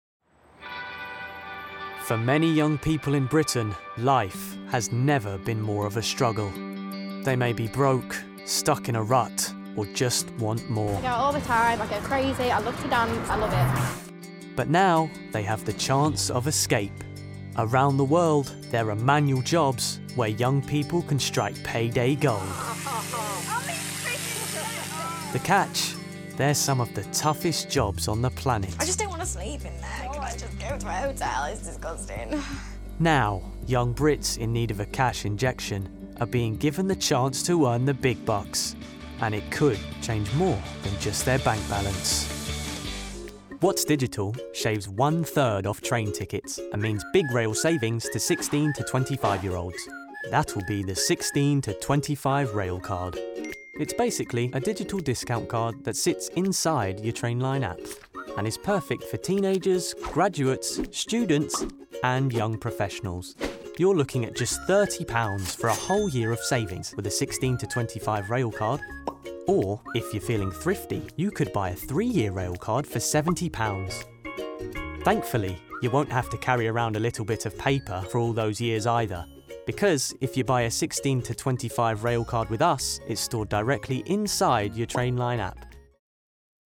with a warm and youthful sound.
Documentary